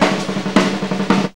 JAZZ FILL 5.wav